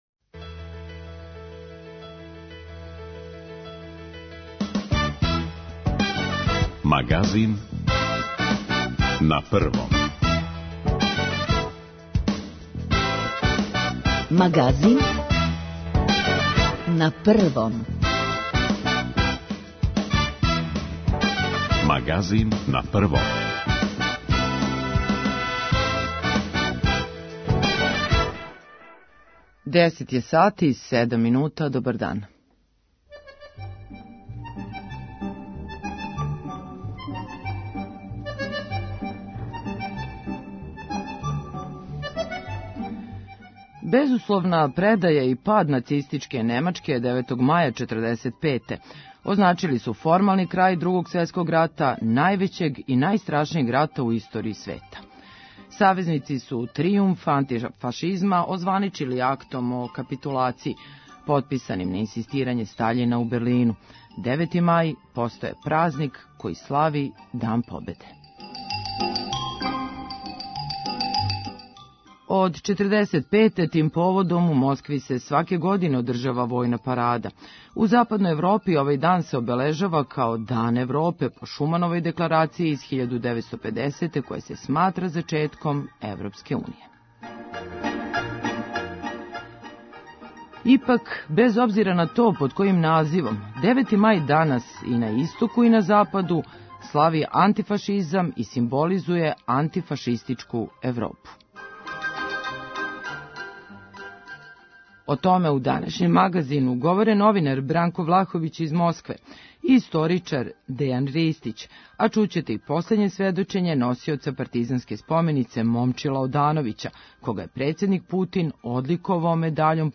Девети мај постао је празник који слави Дан победе. преузми : 9.60 MB Магазин на Првом Autor: разни аутори Животне теме, атрактивни гости, добро расположење - анализа актуелних дешавања, вести из земље и света.